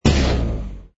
engine_li_fighter_kill.wav